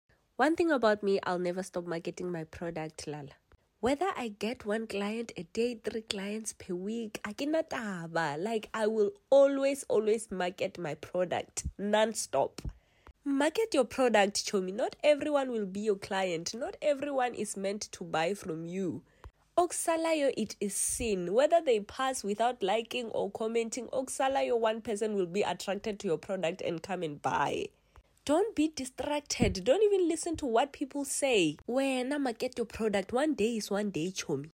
Pure earthy Satisfying Clay Crushing sound effects free download